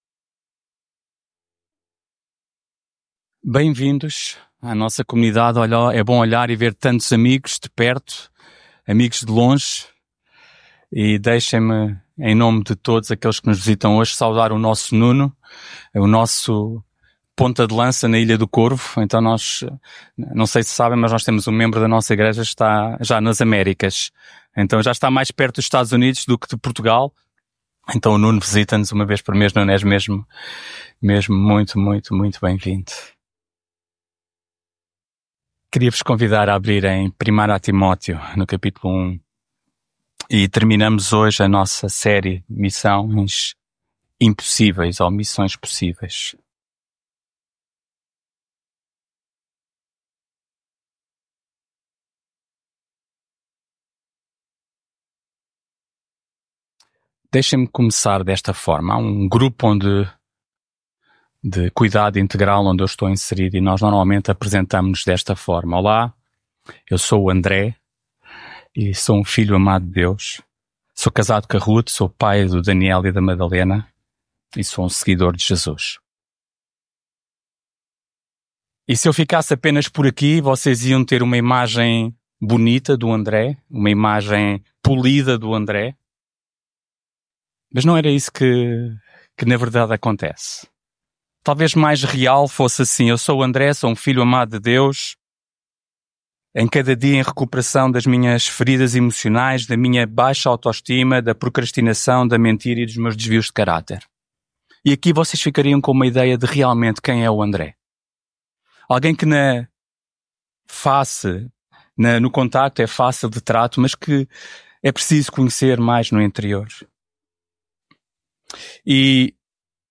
mensagem bíblica Quando percebo que sozinho e por mim próprio eu não consigo alterar os meus comportamentos destrutivos, erros e defeitos de carácter, mudar parece...